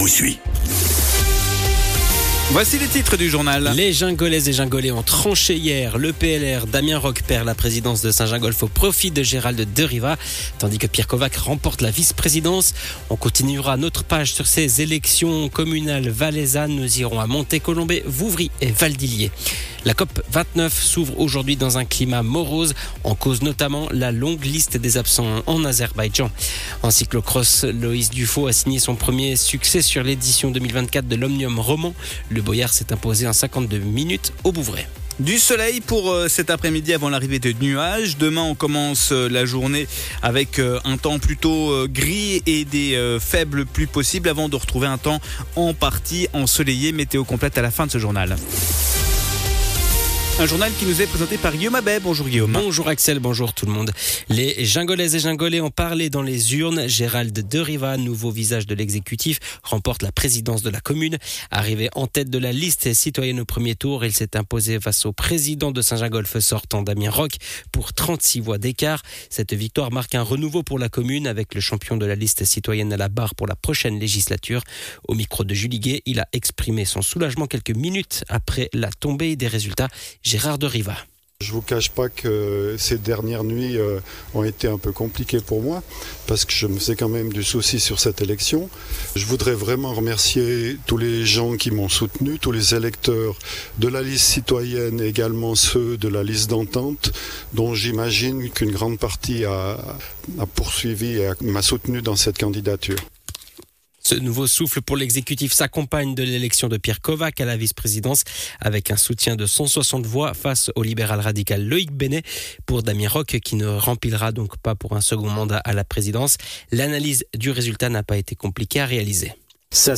Le journal de midi du 11.11.2024
Les infos Radio Chablais en replay